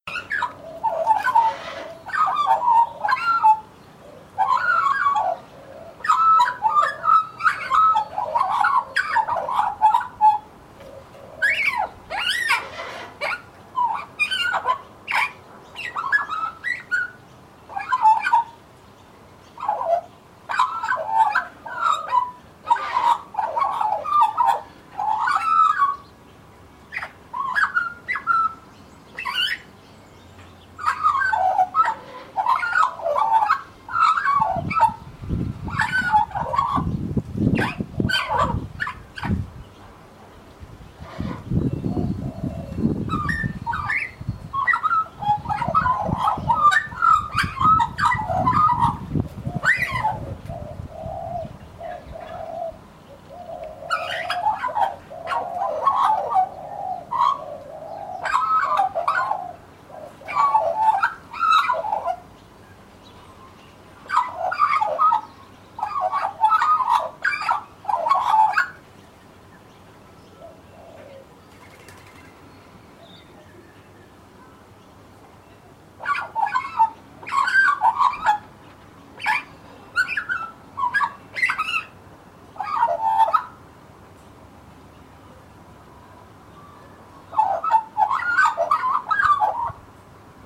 Magpies Song To You
gobbles-singing.mp3